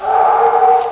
Howl.wav(20kb)
awwhowl.wav